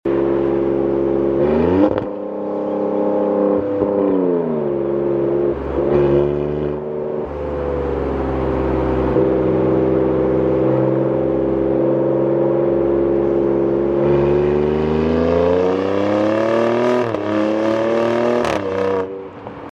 Alfa Romeo Stevlio Quadrifoglio Sounds